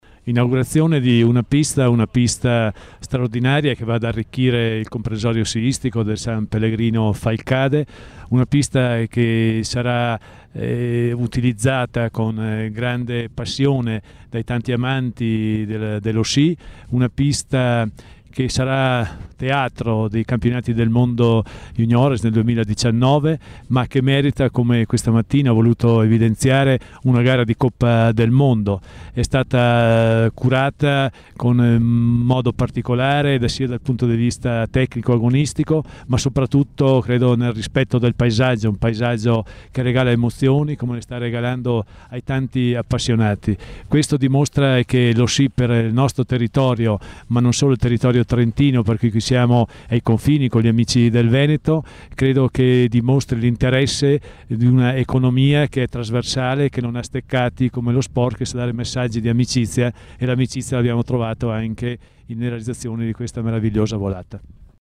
Intervista_audio_9_dicembre_2017_Mellarini.MP3